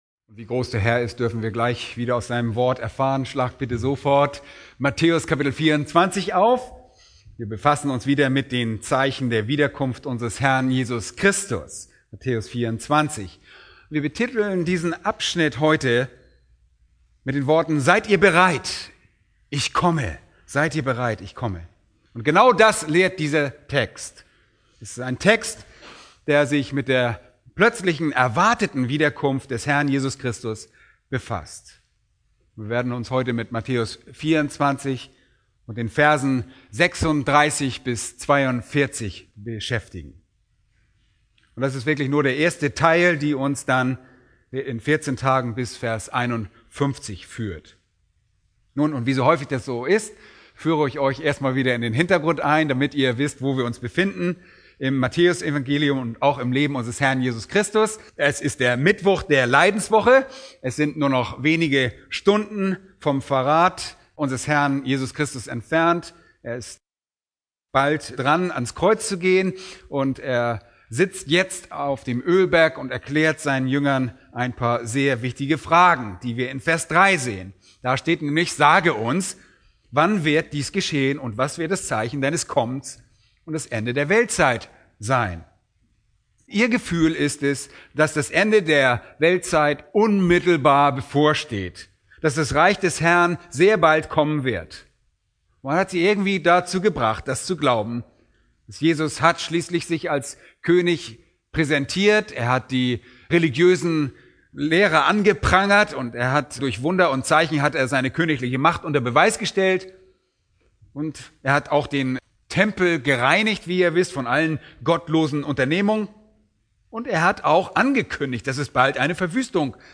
Predigten Übersicht nach Serien - Bibelgemeinde Berlin